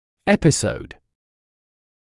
[‘epɪsəud][‘эписоуд]эпизод, случай